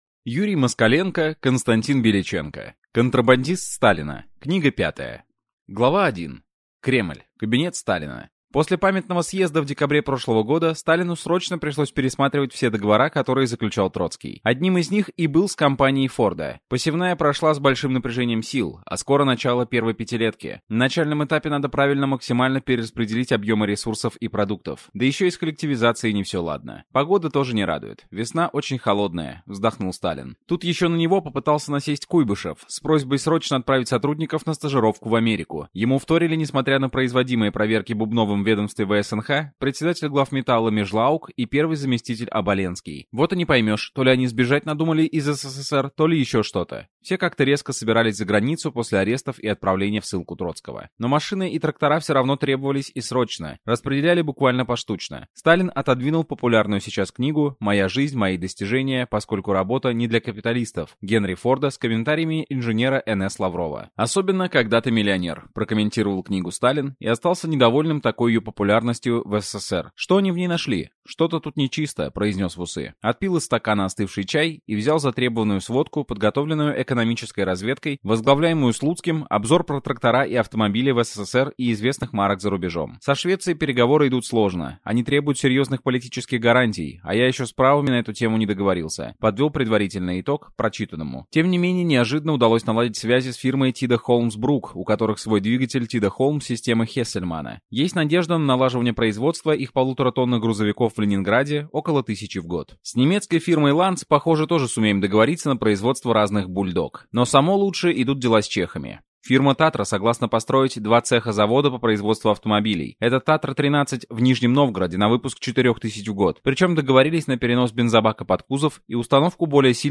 Аудиокнига Контрабандист Сталина Книга 5 | Библиотека аудиокниг